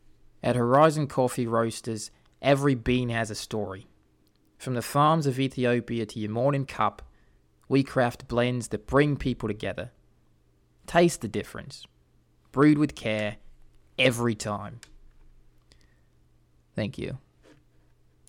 25 year old American male. average American voice with sarcasm and lighthearted tone
Heavy Australian Coffee mock commercial for video